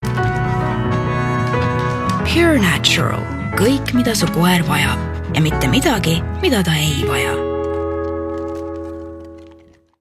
Calm
Soothing
Natural